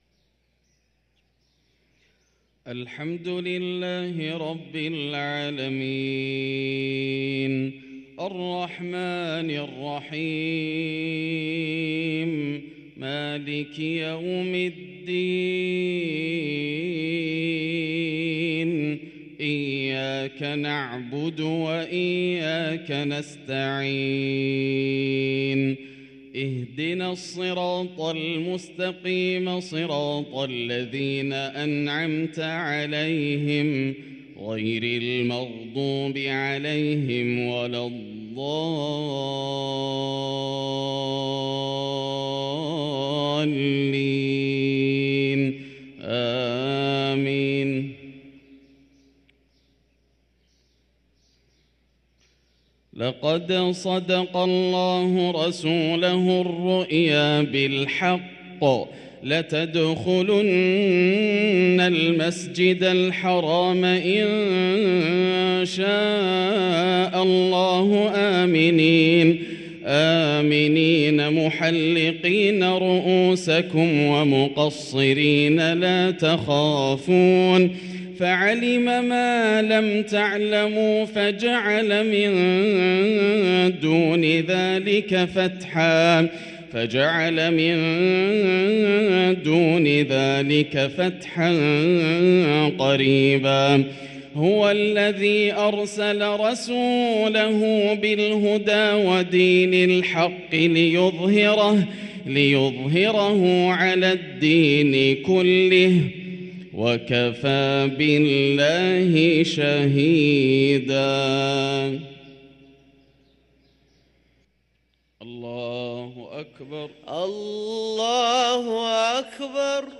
صلاة المغرب للقارئ ياسر الدوسري 10 شعبان 1444 هـ
تِلَاوَات الْحَرَمَيْن .